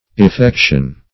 Search Result for " effection" : The Collaborative International Dictionary of English v.0.48: Effection \Ef*fec"tion\, n. [L. effectio: cf. F. effection.]